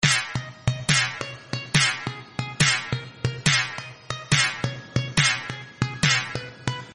kaikottu_17137.mp3